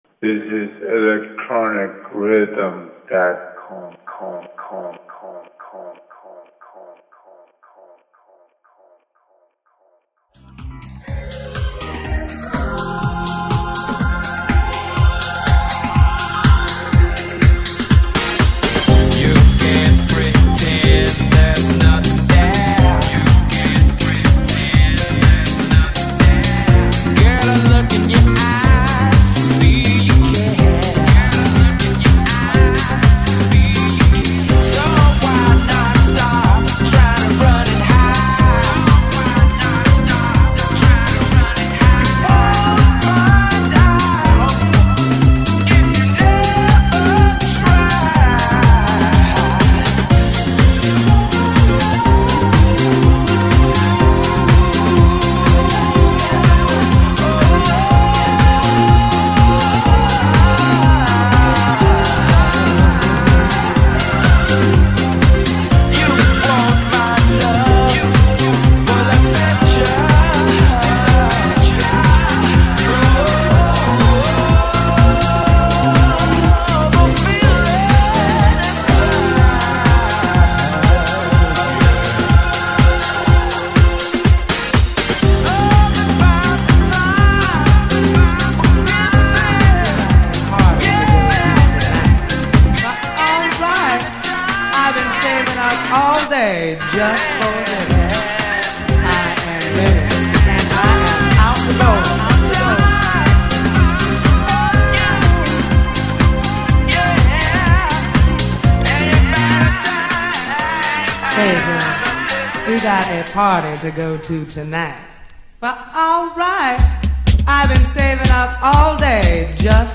CHICAGO HOUSE